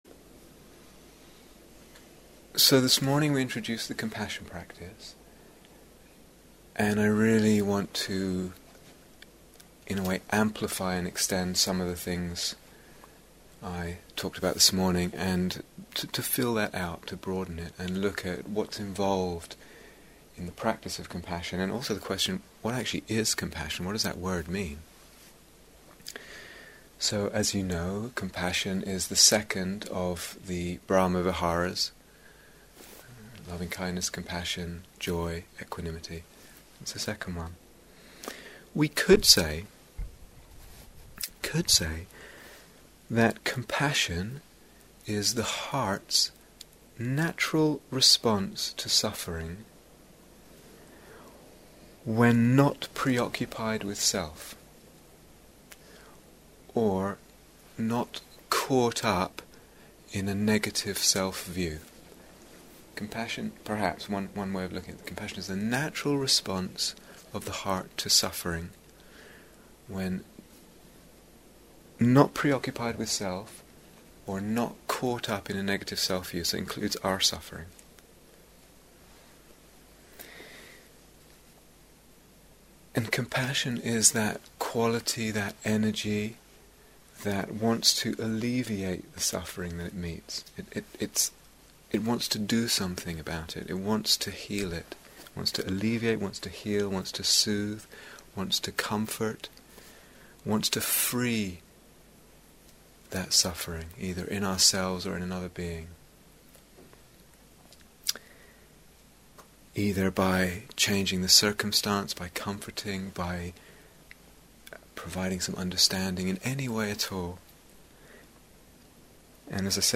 Here is the full retreat on Dharma Seed This series of talks and guided meditations explores the development of the practices of both Lovingkindness and Compassion, with particular emphasis on the radical possibilities of Awakening that they bring.